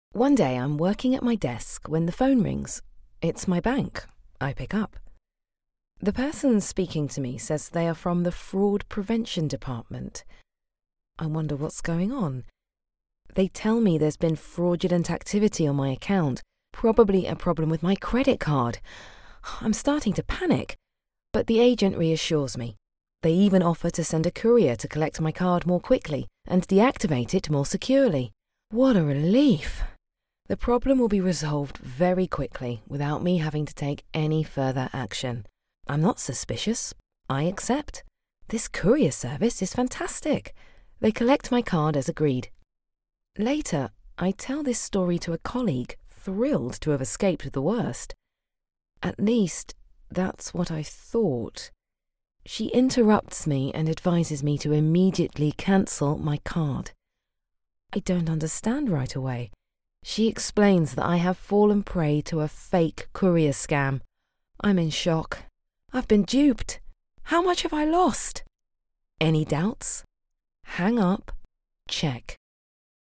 Female
English (British)
Adult (30-50)
Natural Speak